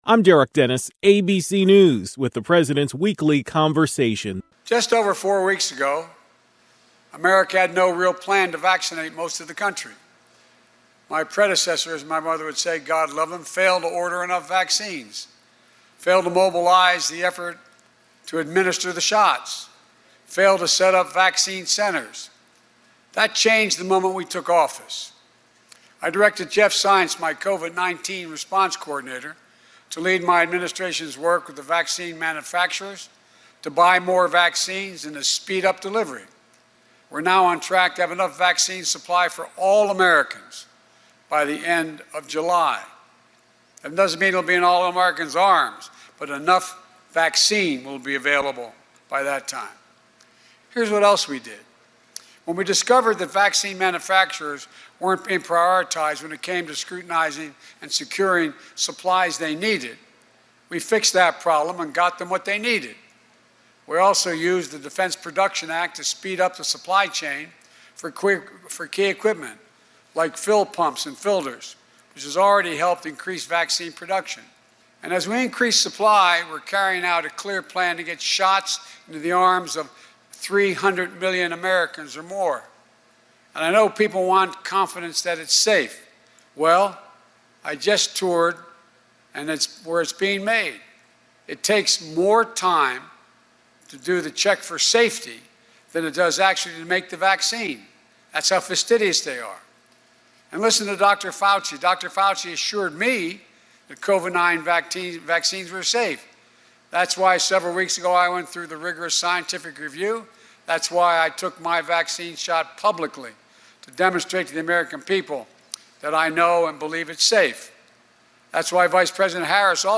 President Biden delivered a speech on the situation with Russia and Ukraine.